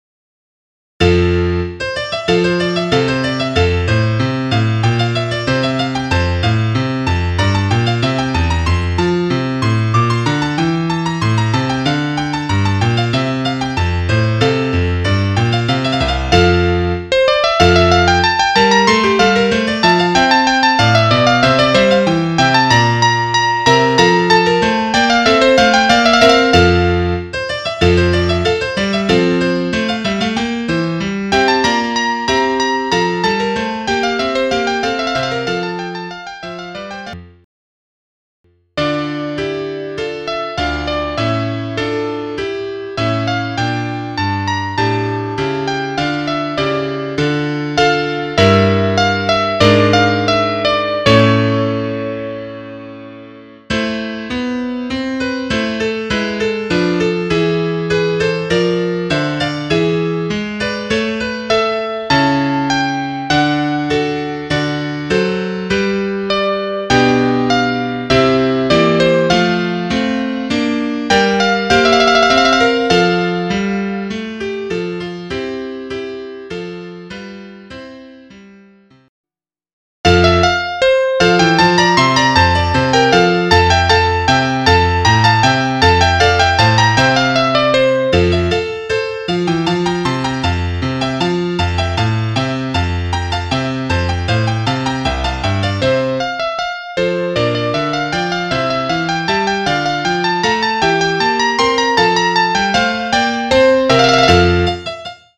für Piccolotrompete
mit Klavierbegleitung.